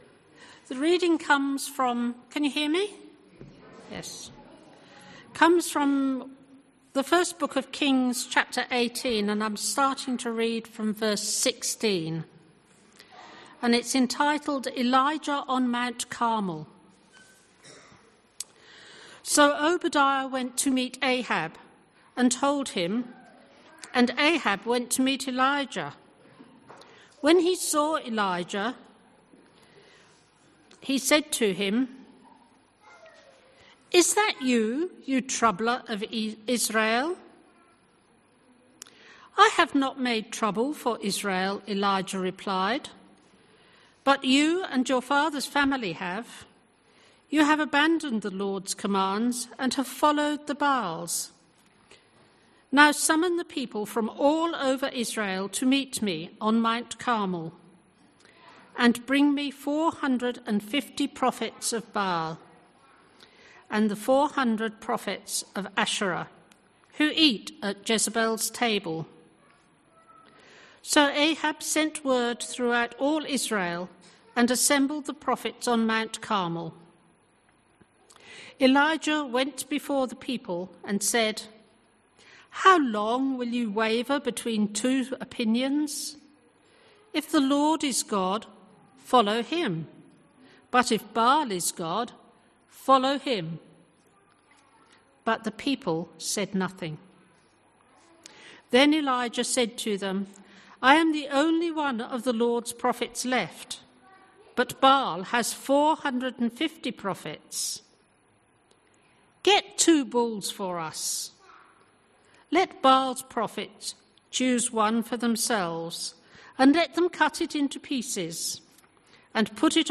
Bible reading and sermon from the 10AM meeting at Newcastle Worship & Community Centre of The Salvation Army. The Bible reading was taken from 1 Kings 18:16-46.